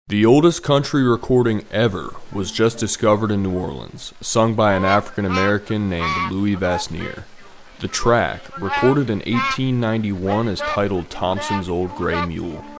The oldest country song recording sound effects free download
sung by an African American